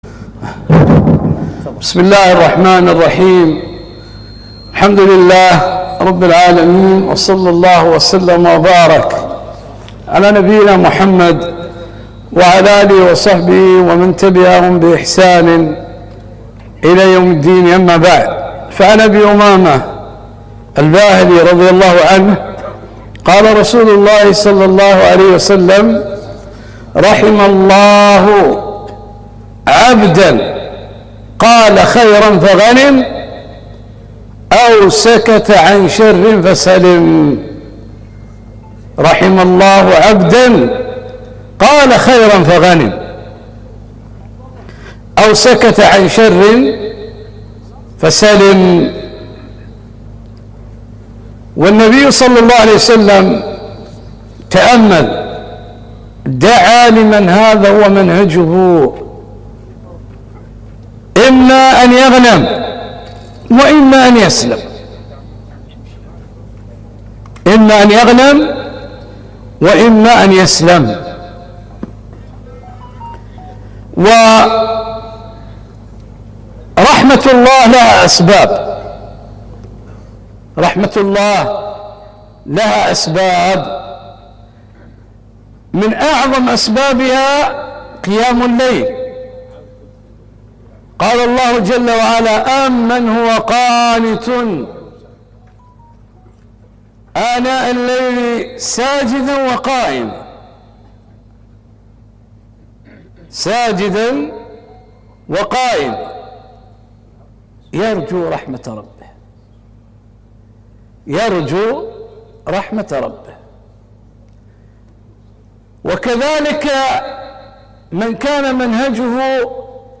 محاضرة - رحم الله عبدًا قال خيرًا فغنم، أو سكت عن سوء فسلم